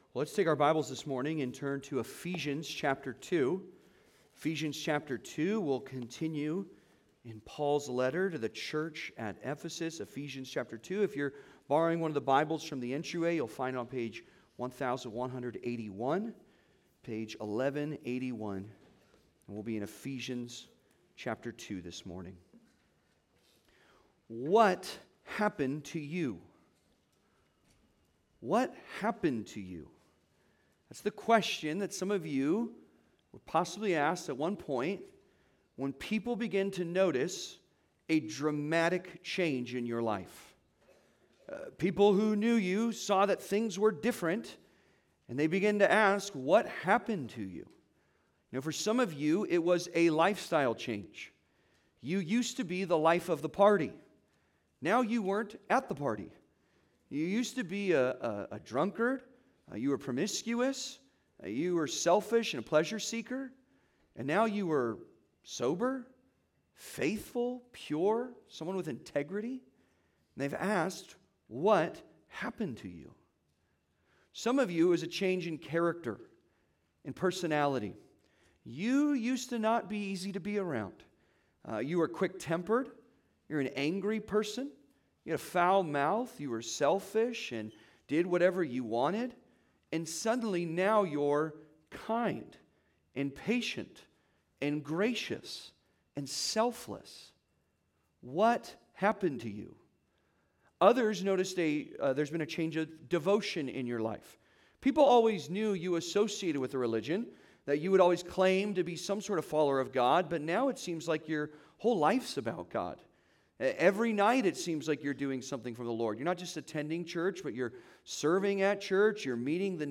The New You (Sermon) - Compass Bible Church Long Beach